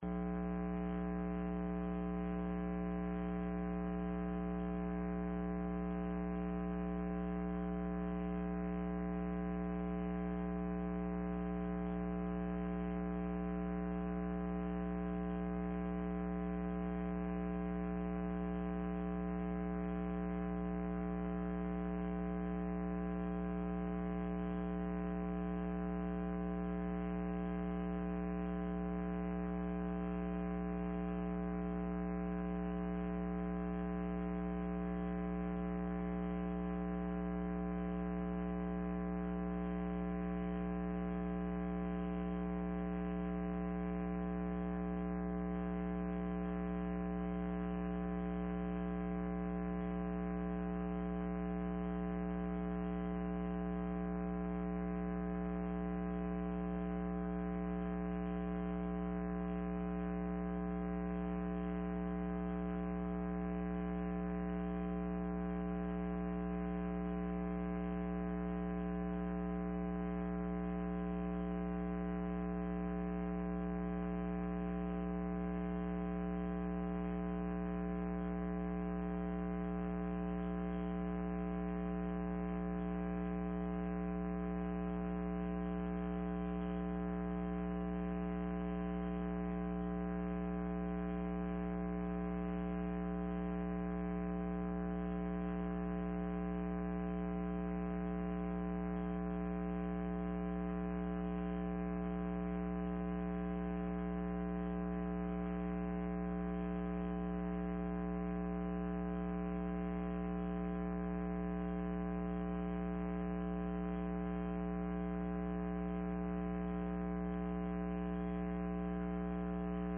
الدرس 178